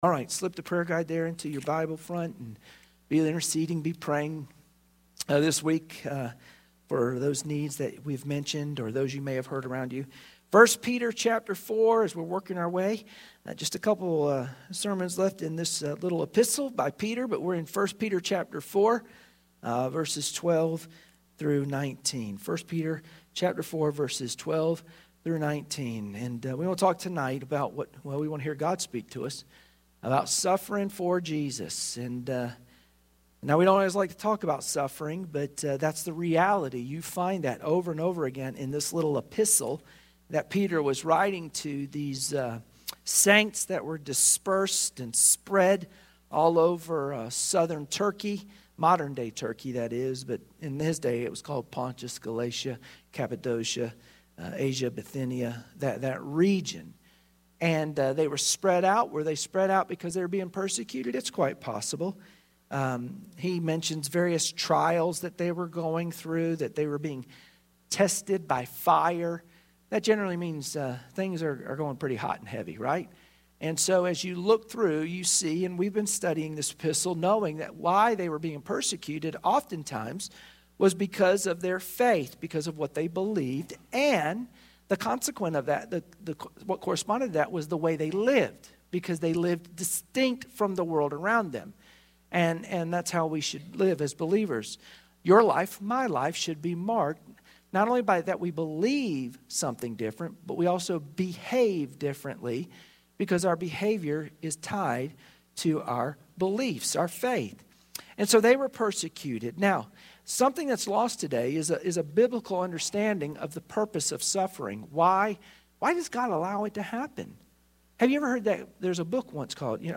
Wednesday Prayer Mtg Passage: 1 Peter 4:12-19 Service Type: Wednesday Prayer Meeting Share this